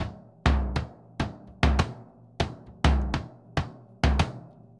Bombo_Candombe_100_1.wav